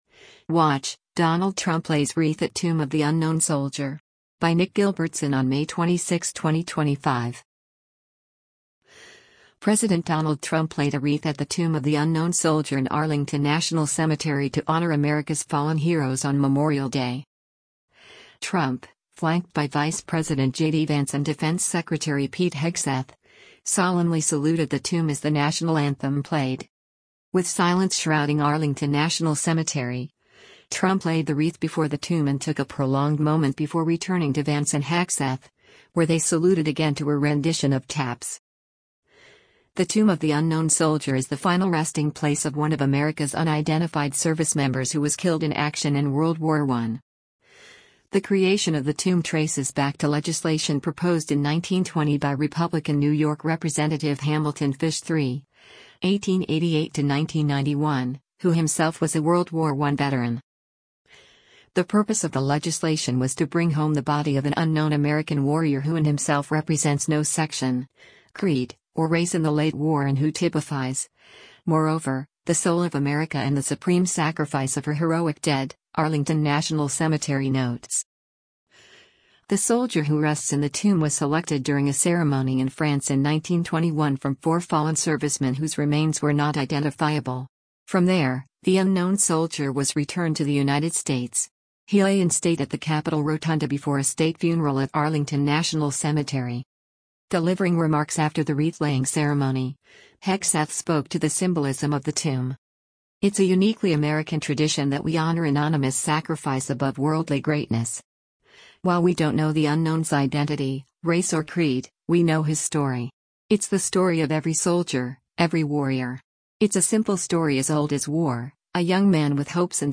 Trump, flanked by Vice President JD Vance and Defense Secretary Pete Hegseth, solemnly saluted the tomb as the National Anthem played.
With silence shrouding Arlington National Cemetery, Trump laid the wreath before the tomb and took a prolonged moment before returning to Vance and Hegseth, where they saluted again to a rendition of “Taps.”